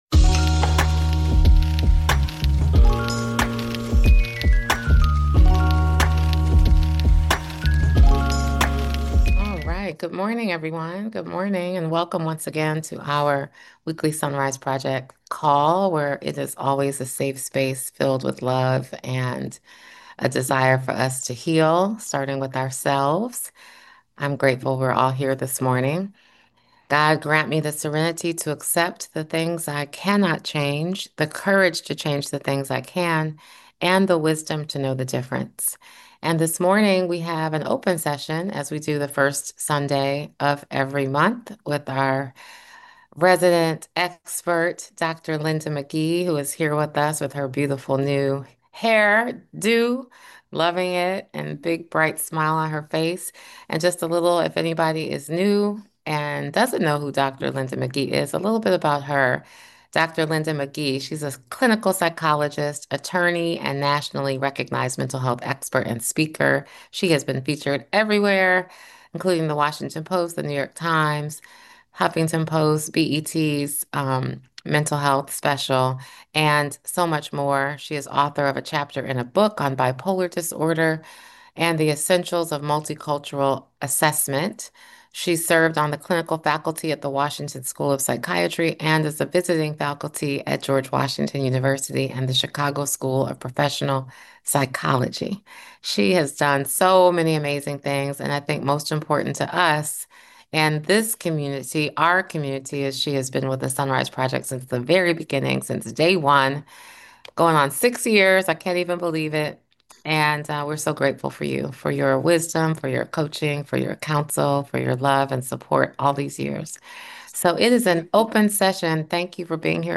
This open format creates a safe space for connection, reflection, and real-world guidance on the topics that matter most.